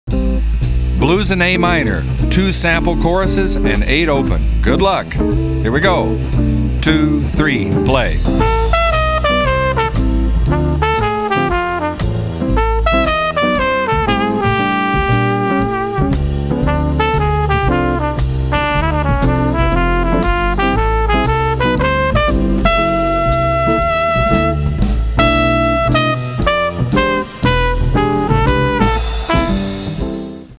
Saxophones and Flute
Trumpet and Flugelhorn
Guitar
Piano
Bass
Drums.